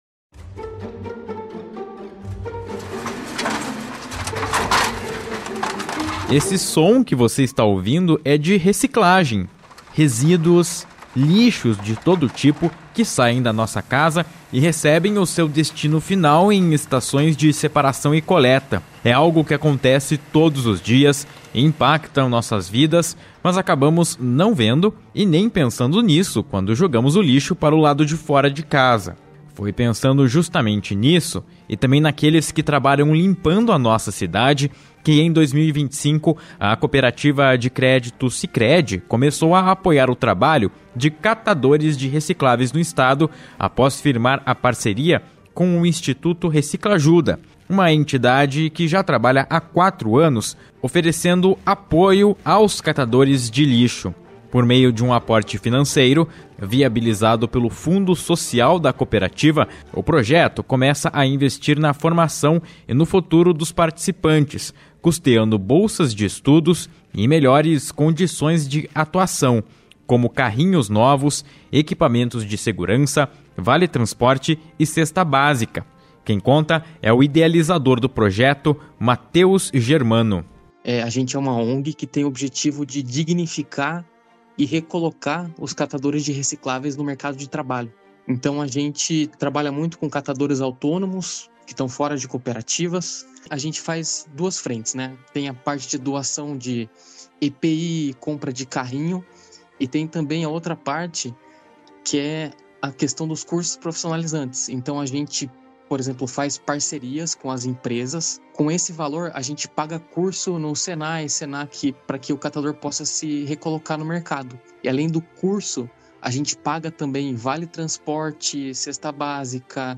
Reportagem 3 – Cooperativismo abraça causas sociais